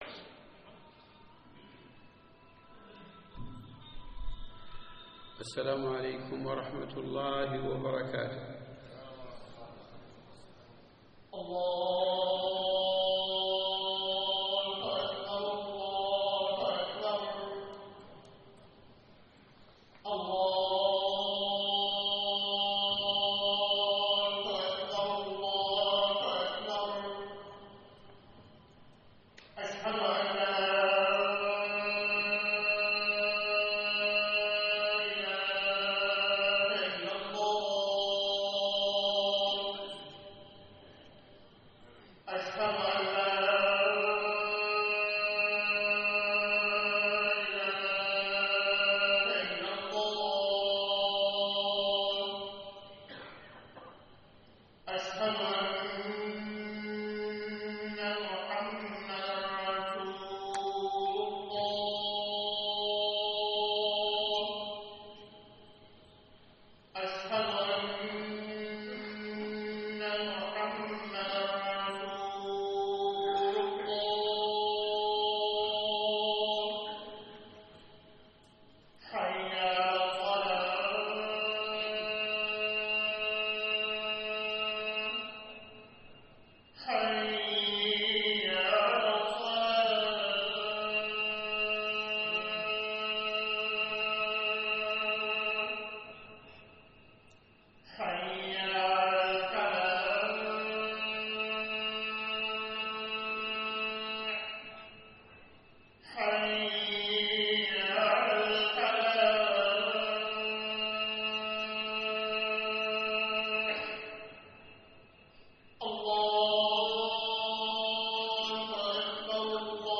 خطبة صلاة الجمعة